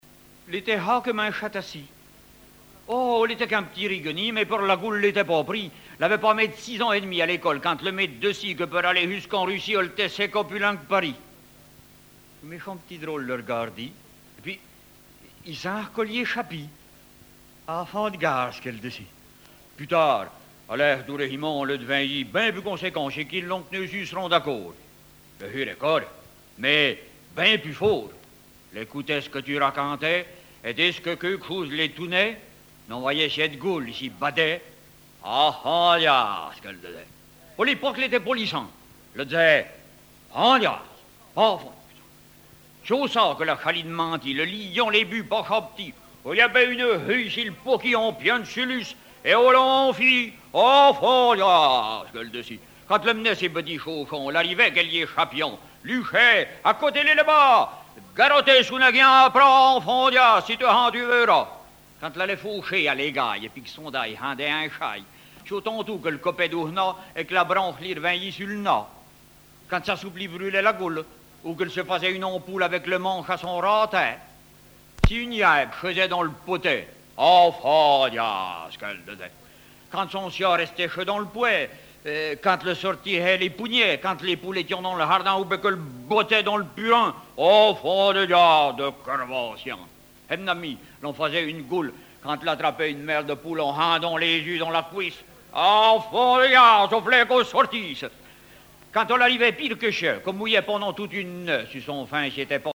Genre sketch
histoires en patois poitevin
Catégorie Récit